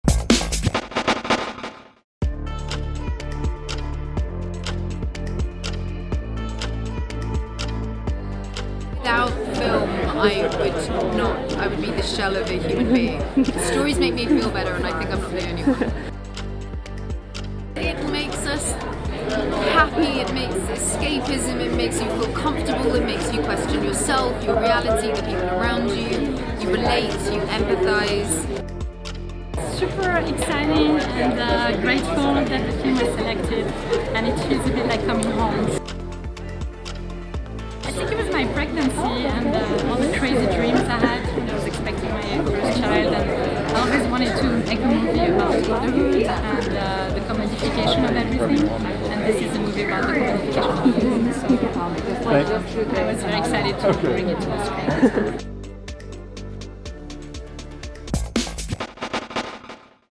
Emilia Clarke States the Deep Importance of Film at the Sundance Premiere of The Pod Generation
Emilia Clarke came out for the premiere of her film The Pod Generation at the Sundance Film Festival in Park City, Utah, on Thursday night.